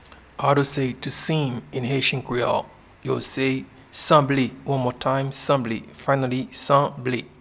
Pronunciation and Transcript:
to-Seem-in-Haitian-Creole-Sanble.mp3